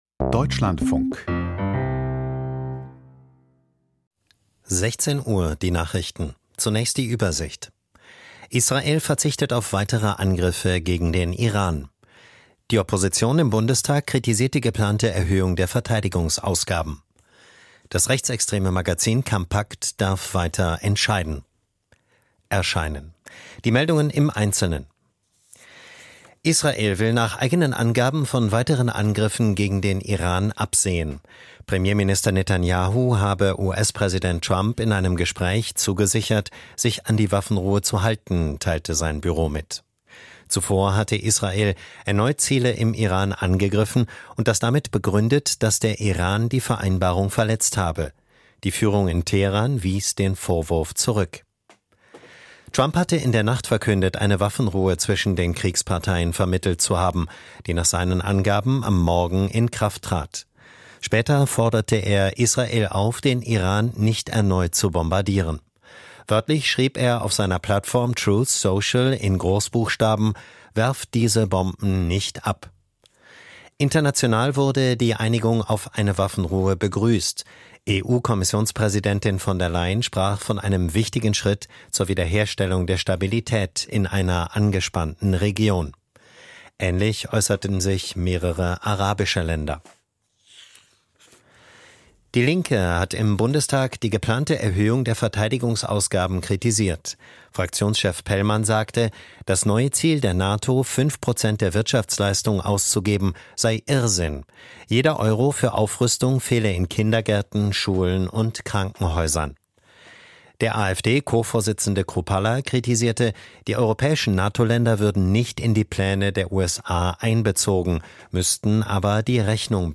Die Nachrichten vom 24.06.2025, 16:00 Uhr
Die wichtigsten Nachrichten aus Deutschland und der Welt.
Aus der Deutschlandfunk-Nachrichtenredaktion.